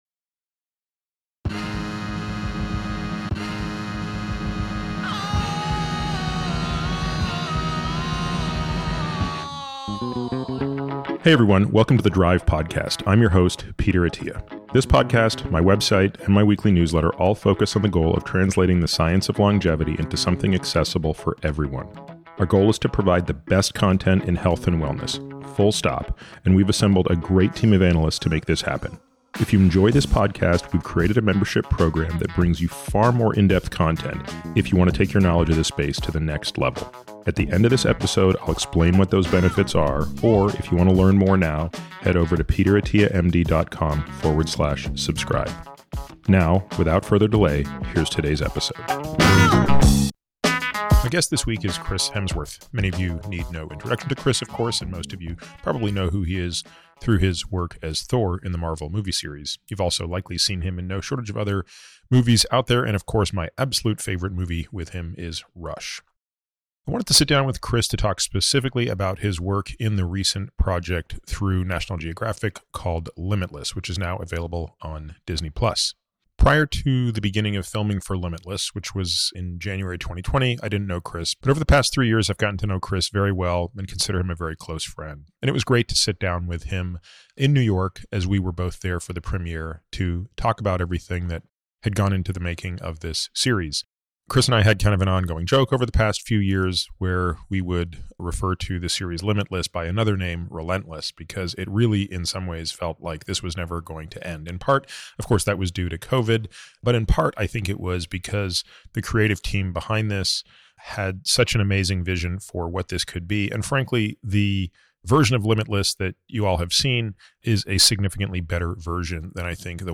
Chris Hemsworth, best known for his role as Thor in the Marvel movies, joins Peter to discuss his recent work in the docuseries called Limitless .